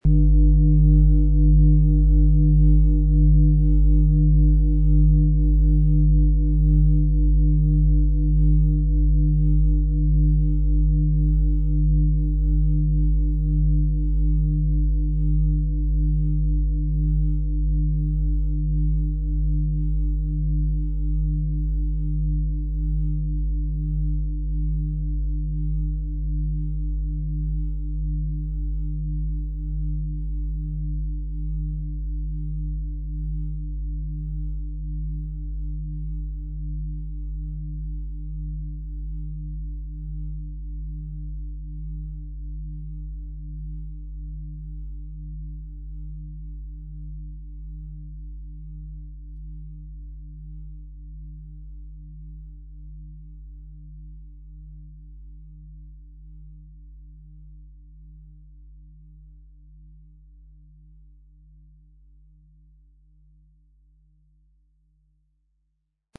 Planetenton 1
XXXL Planeten-Fußreflexzonen-Klangschale mit Eros & Uranus
• Tiefster Ton: Uranus